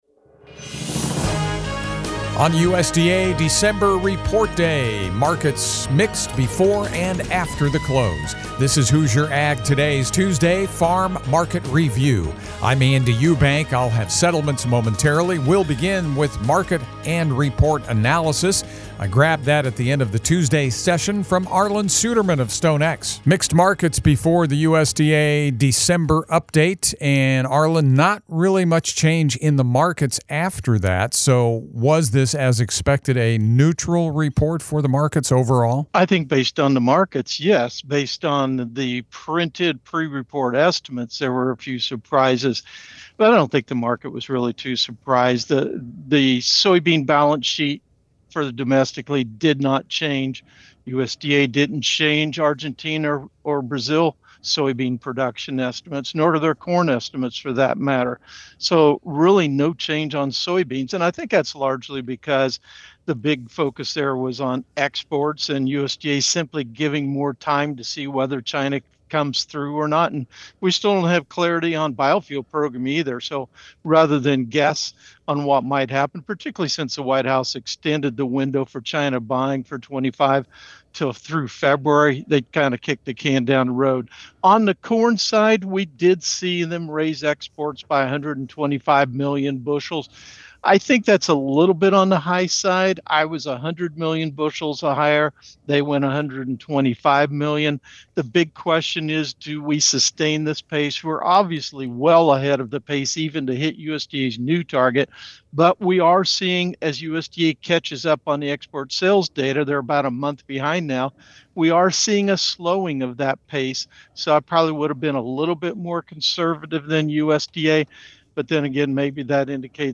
analysis